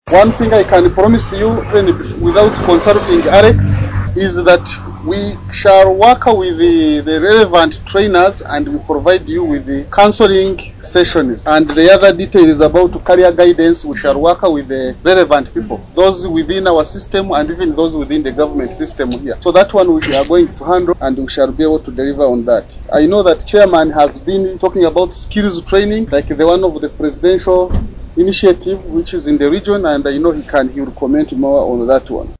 Speaking to the Group Members